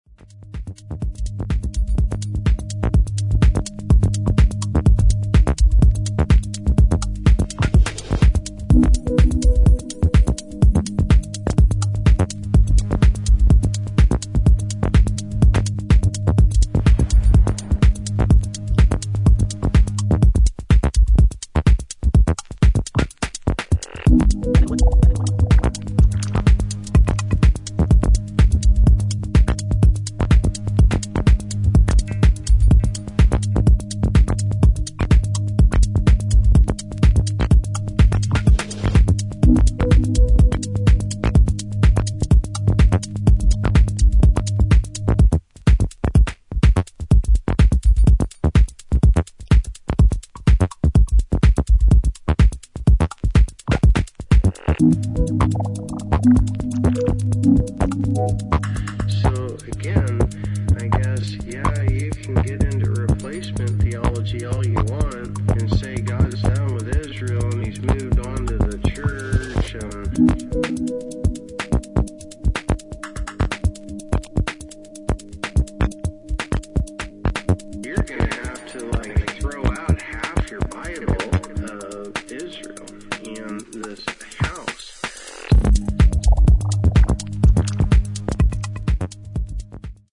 303風味のアシッドなベースサウンドをレイヤー的に用い、ドライブ感のある現代的なミニマルハウスへと昇華しています。